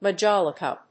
音節ma・jol・i・ca 発音記号・読み方
/mədʒάlɪkə(米国英語), mʌˈdʒɔ:lʌkʌ(英国英語)/